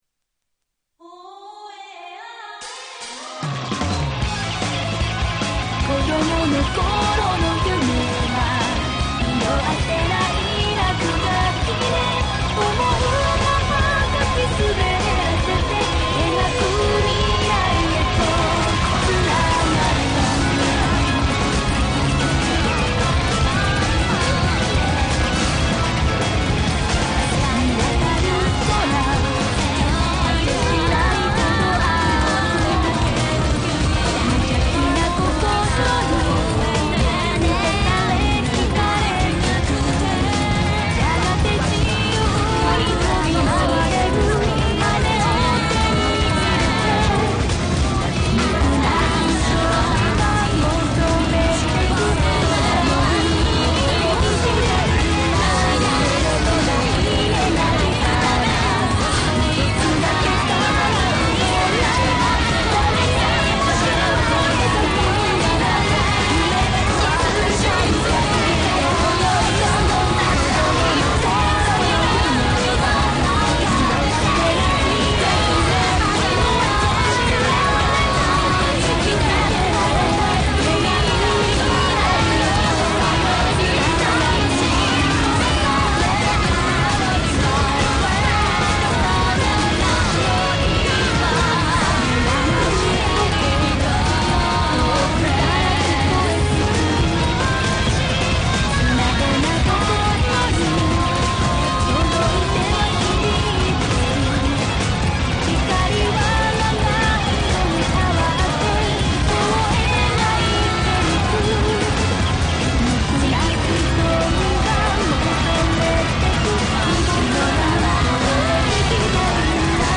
音楽を五曲くらい重ねたら聞き取れるかなー。
「なんだこのカオス。」――ご尤もです。